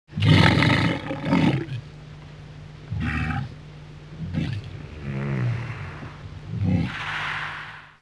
walrus.wav